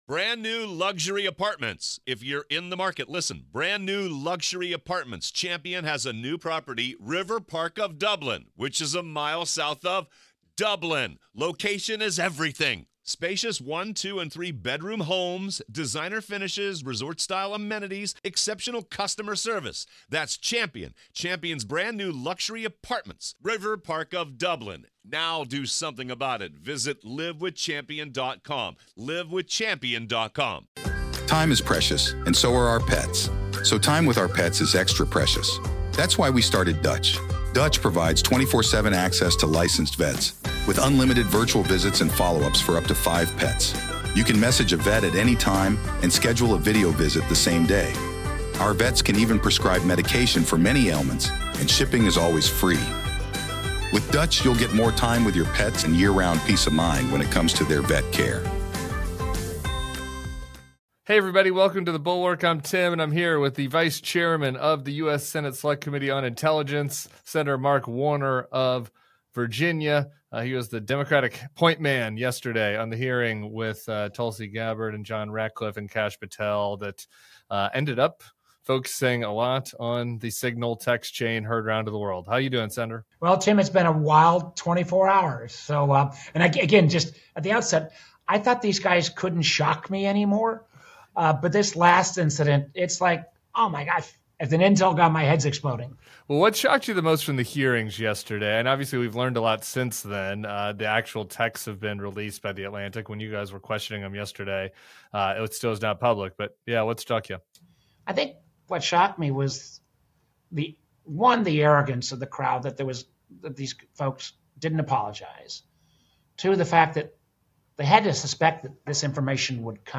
Sen. Mark Warner joins Tim Miller to break down the Signal chat scandal, national security concerns, and troubling reports of legal refugees being deported without due process.